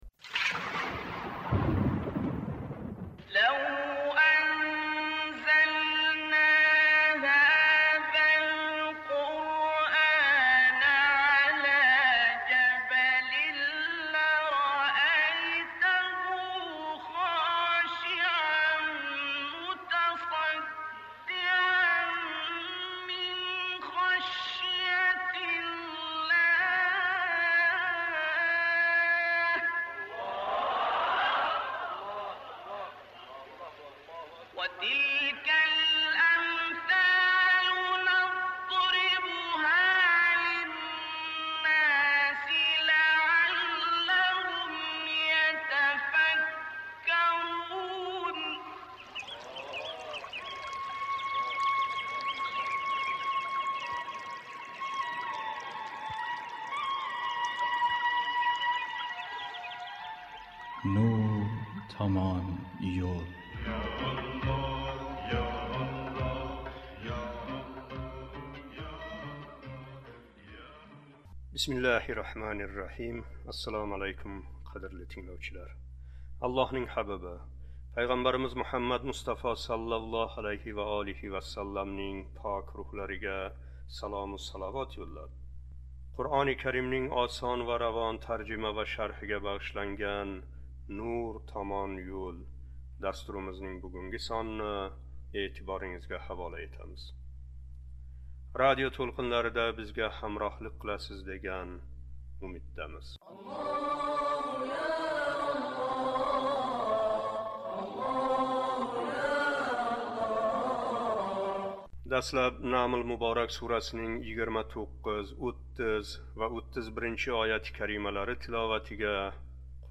Дастлаб "Намл" муборак сурасининг 29-31-ояти карималари тиловатига қулоқ тутамиз:
Энди “Намл” муборак сураси 32-33--ояти карималарининг тиловатига қулоқ тутамиз.